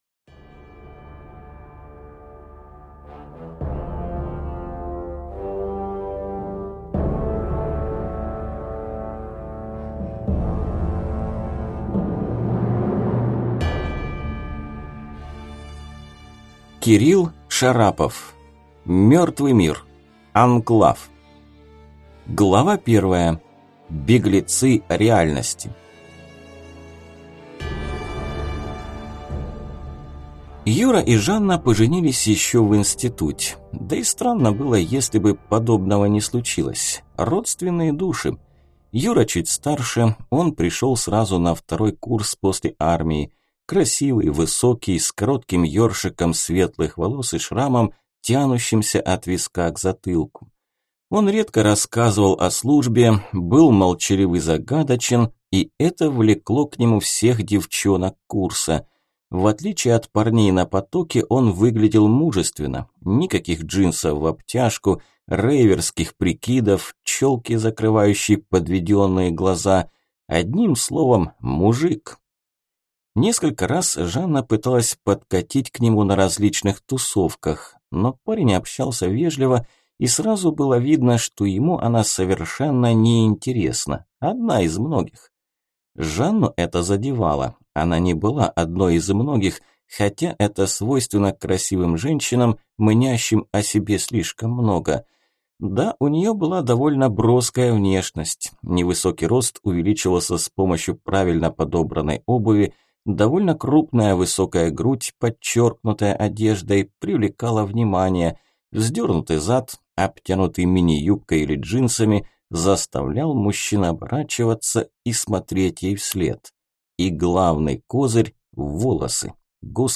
Аудиокнига Мёртвый мир. Анклав | Библиотека аудиокниг